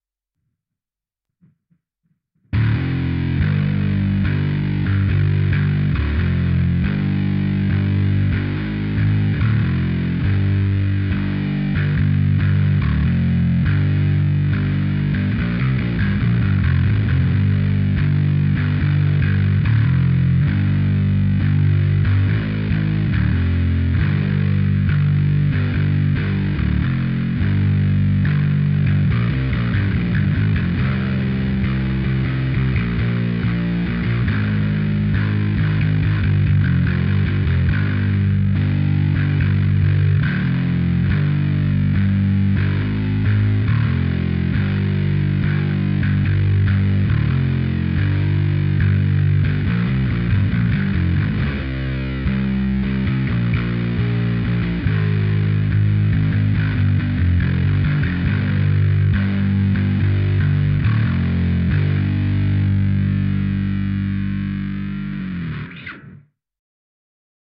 Stoner/Doom/Sludge - die sumpfigen Untiefen